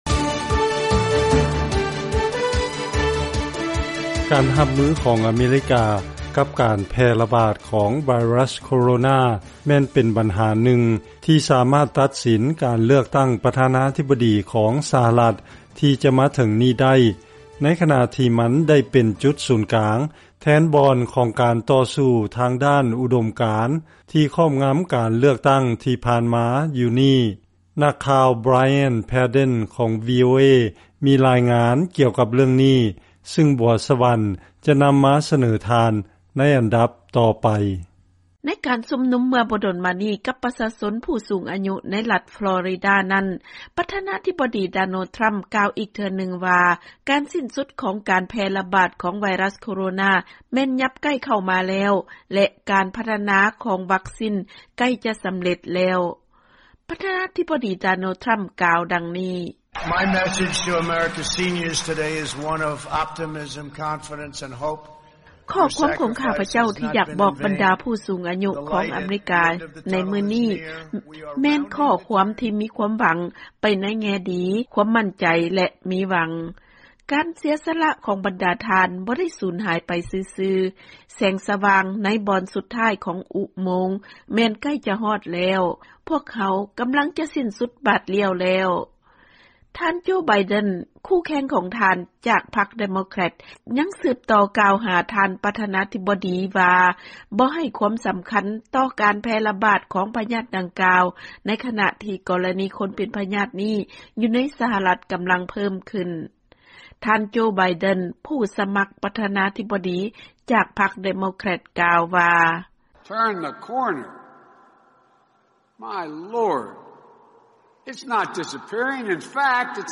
ເຊີນຟັງລາຍງານກ່ຽວກັບບັນຫາໂຄວິດ-19 ໃນການໂຄສະນາຫາສຽງເລືອກຕັ້ງປະທານາທິບໍດີ ສຫລ